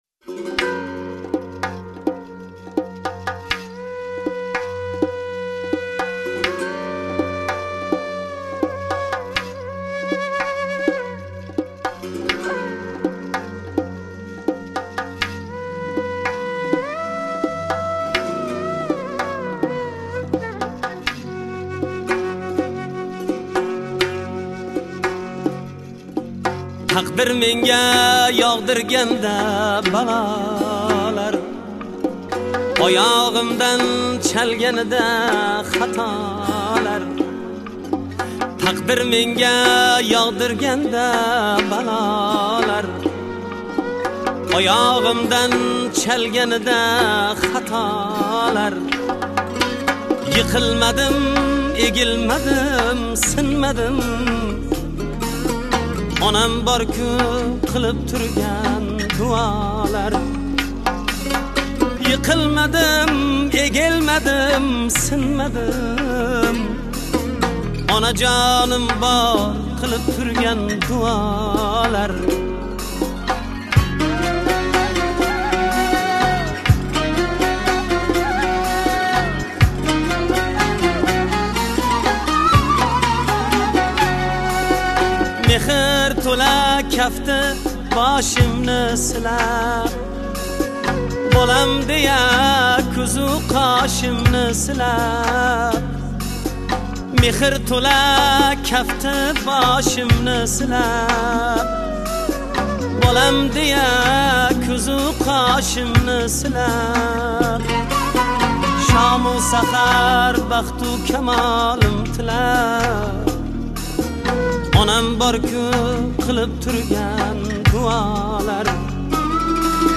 Ўзбекистон мусиқаси